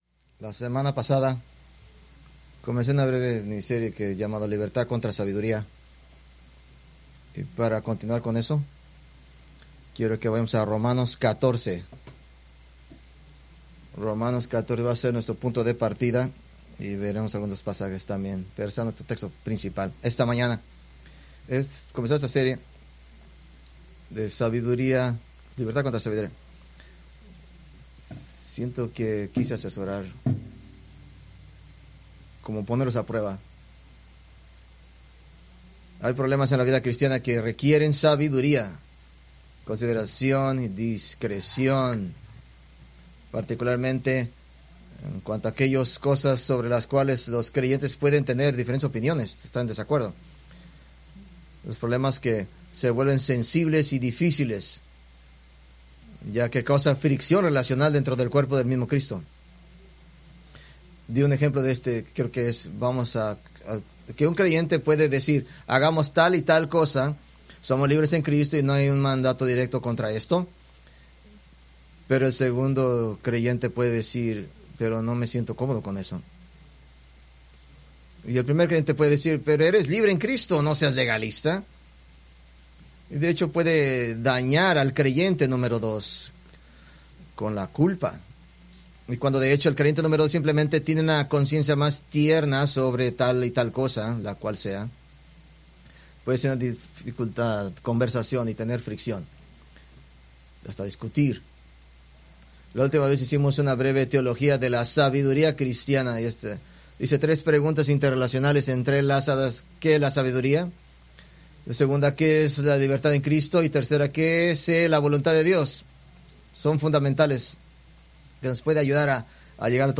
Preached September 14, 2025 from Escrituras seleccionadas